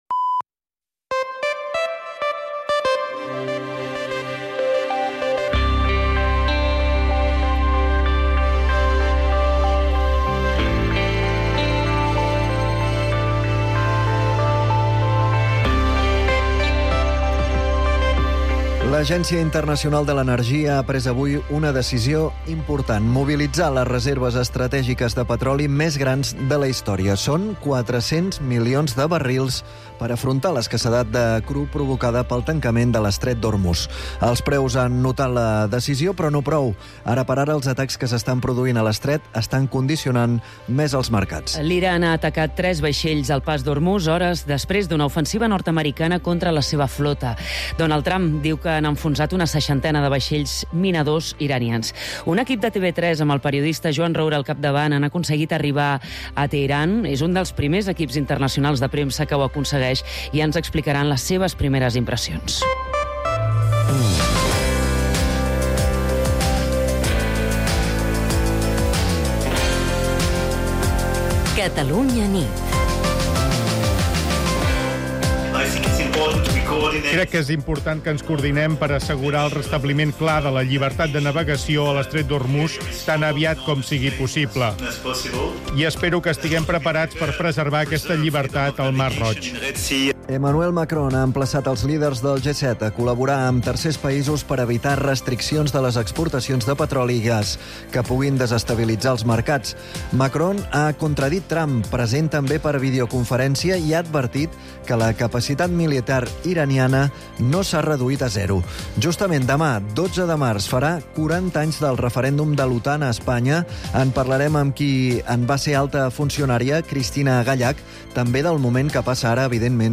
El compromís d’explicar tot el que passa i, sobretot, per què passa és la principal divisa del “Catalunya nit”, l’informatiu nocturn de Catalunya Ràdio